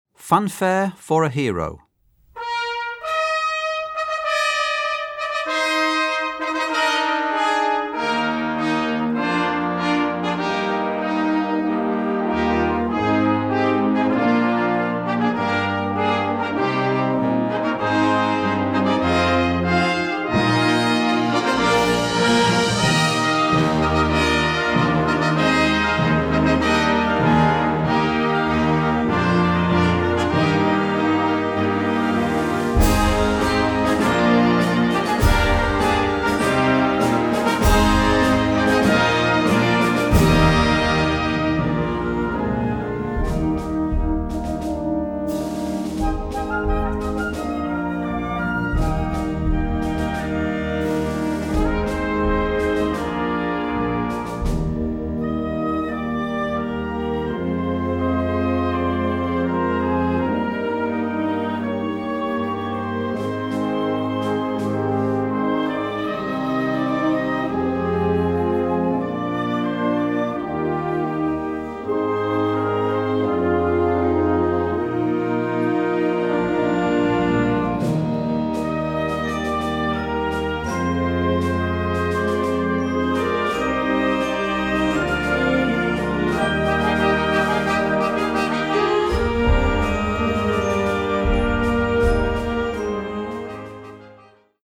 Gattung: Eröffnungsfanfare
Besetzung: Blasorchester